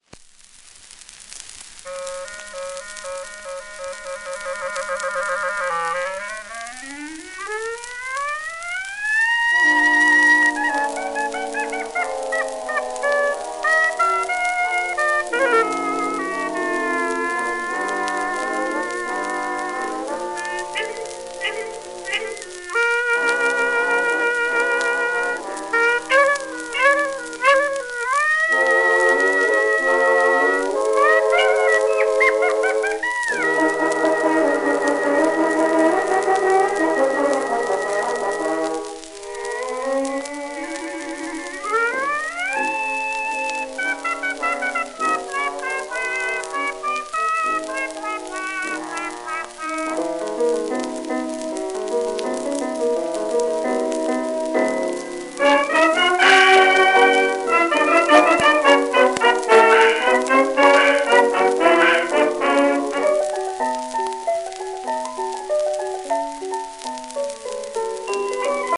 [Jun 10,1924]＊1回目の録音（アコースティック）
盤質B+, 面擦れ,浅いキズ　　※画像クリックで試聴音源が流れます